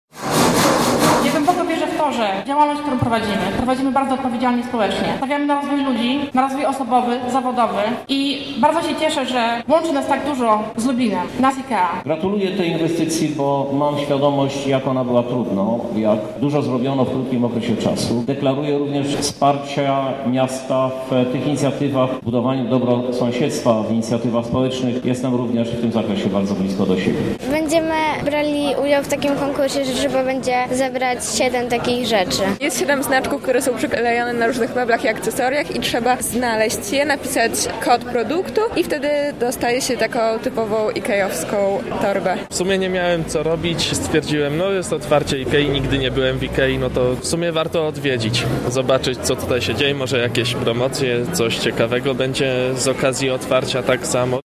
Tradycyjnie, otwarcie poprzedziło nie przecięcie wstęgi, a przepiłowanie beli drewna: